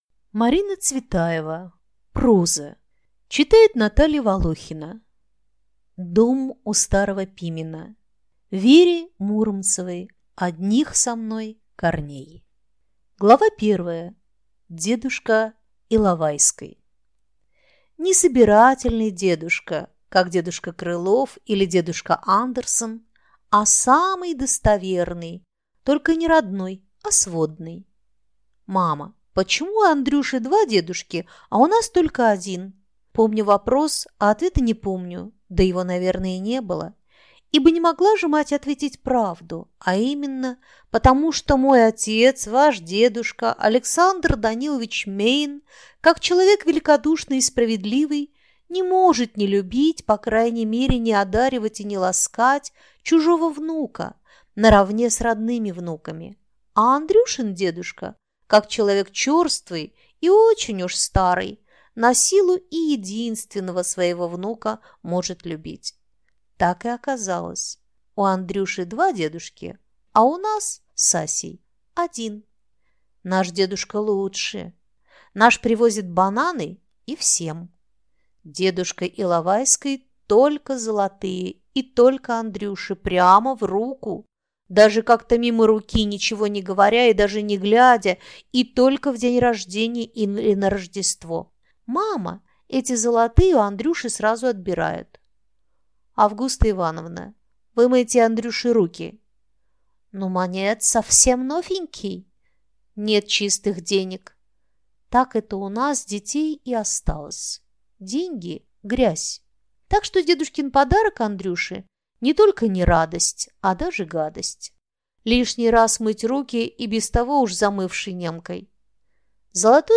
Аудиокнига Дом у Старого Пимена | Библиотека аудиокниг